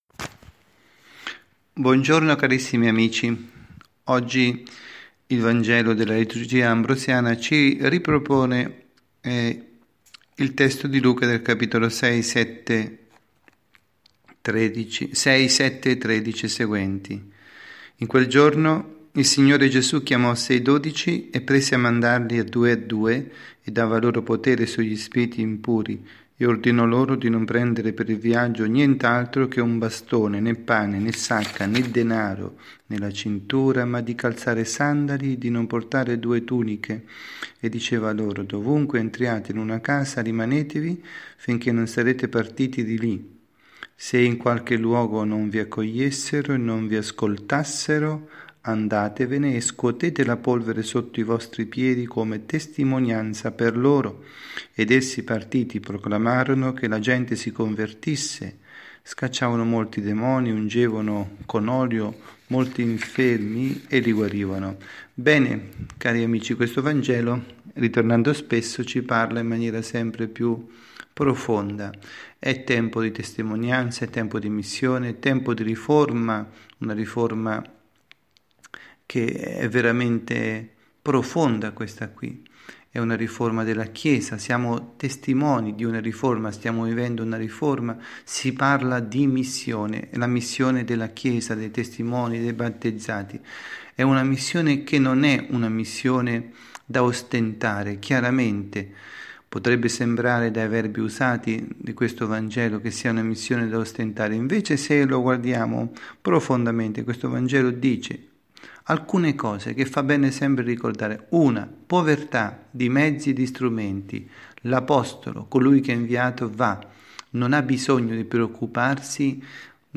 Catechesi